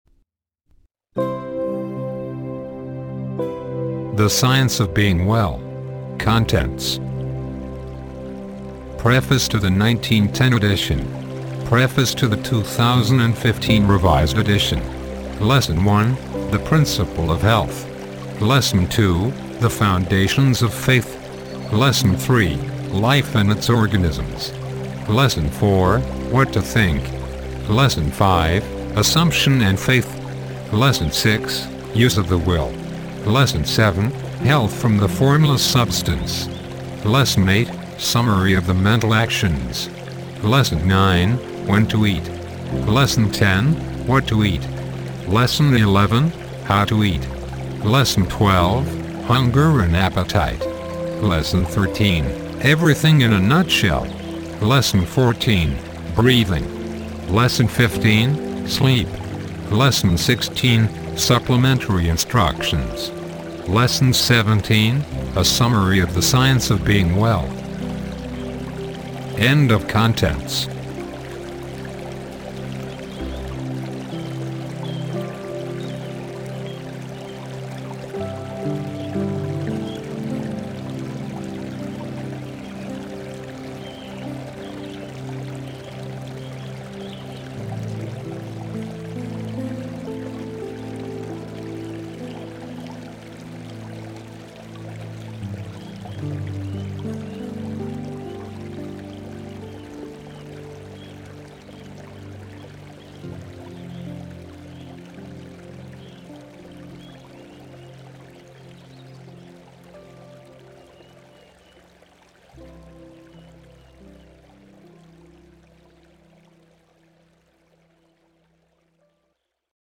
"Contents", "Preface to 1910 Edition", and "Preface to 2015 Edition", do not contain a subliminal voice
A synthesized voice is used, for maximum effect, without the possibility of unwanted tonal or expressive imprinting.